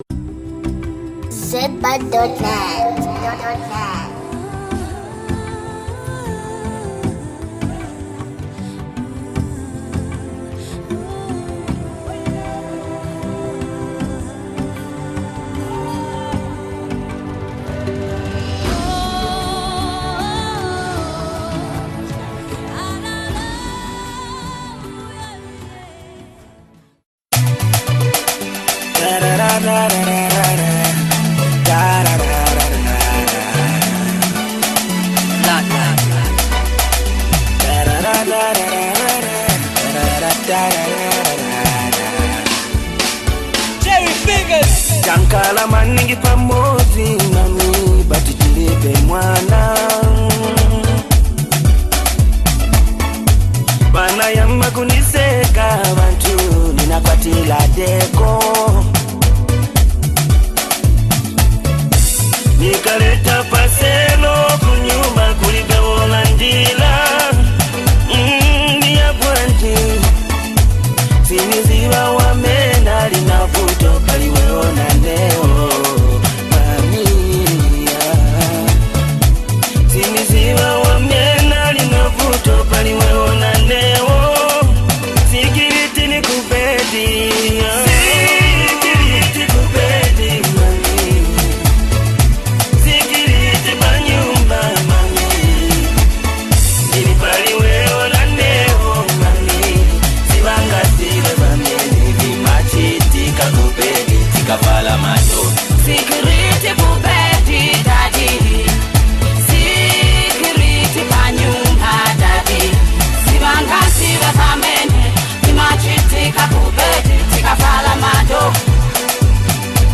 Genre: Zambian Music